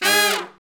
Index of /90_sSampleCDs/Roland L-CD702/VOL-2/BRS_R&R Horns/BRS_R&R Falls